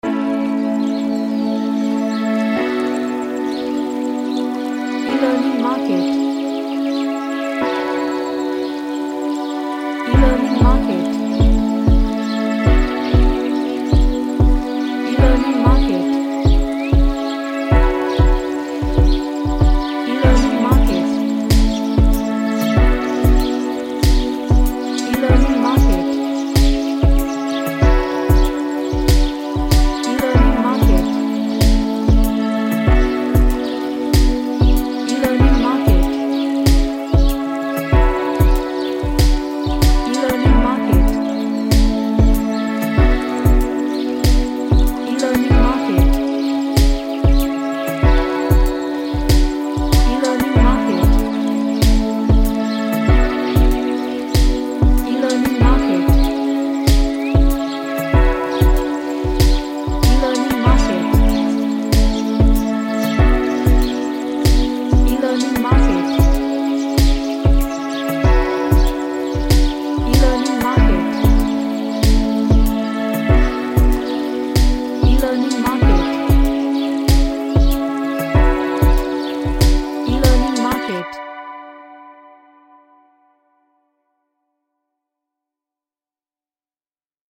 A Relaxing track with Birds chirping and pleasant vibe.
Relaxation / Meditation